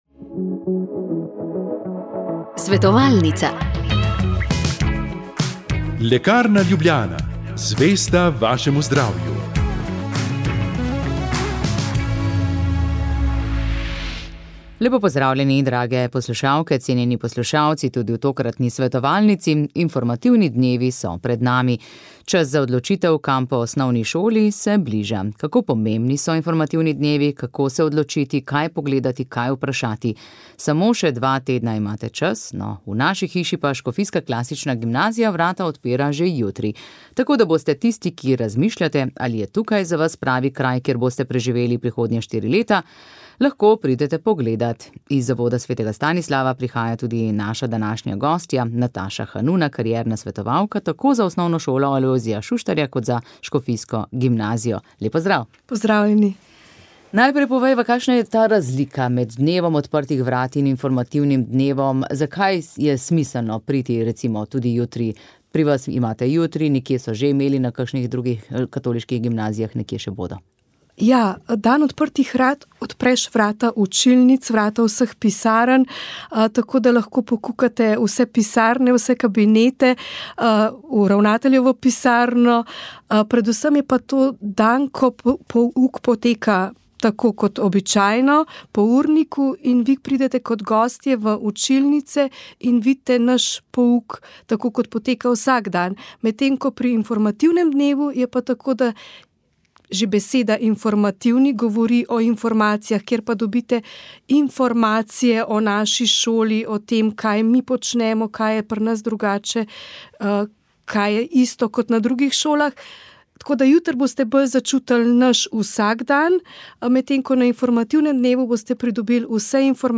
Sveta maša
Sv. maša iz cerkve sv. Marka na Markovcu v Kopru 24. 1.